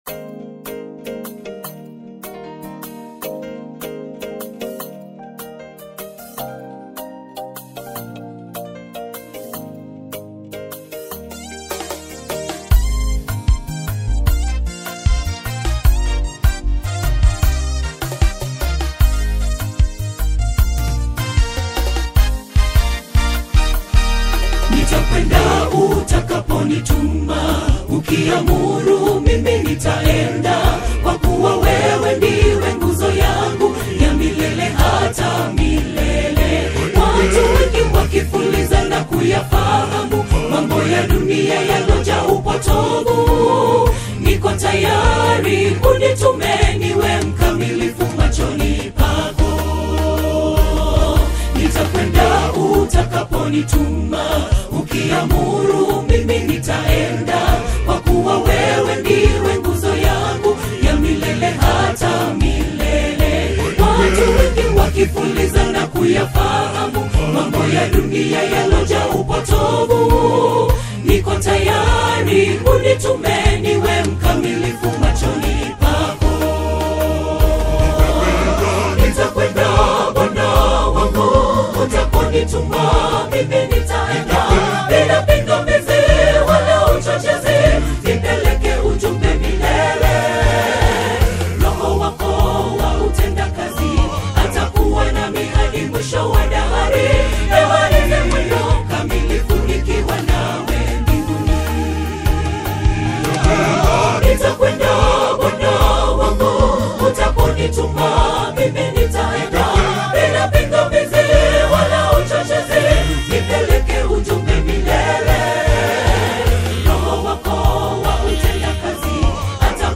lush, atmospheric harmonies